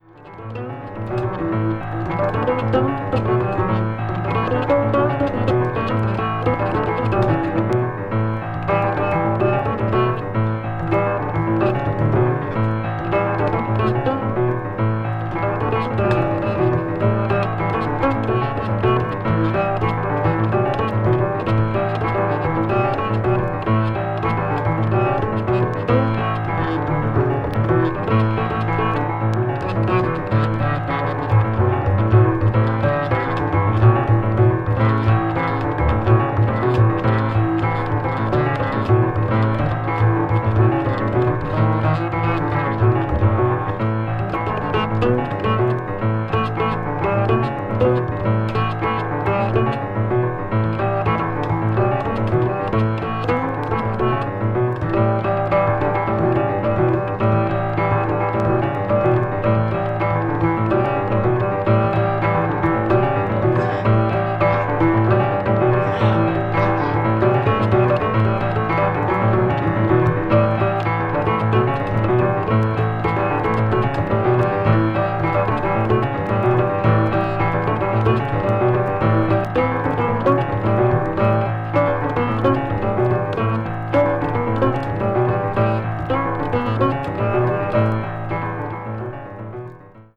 contemporary jazz